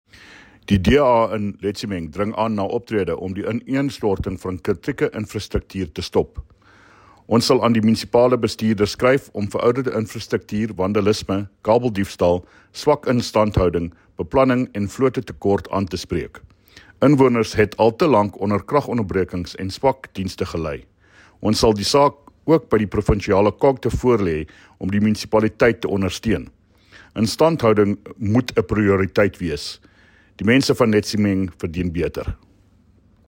Afrikaans soundbite by David Mc Kay MPL, and